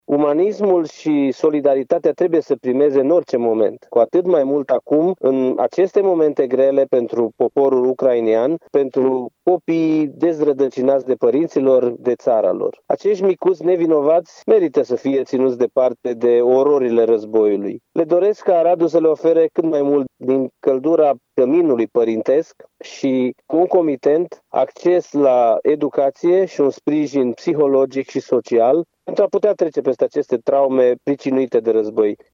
Micii ucraineni care frecventează Şcoala „Avram Iancu” au decupat și lipit modele pascale pe suporturi din carton alături de colegii lor din clasa a III A. Acţiunea este parte a campaniei „Împreună de Paște”, spune președintele Consiliului Județean Arad, Iustin Cionca.
Iustin-Cionca-refugiati-Paste.mp3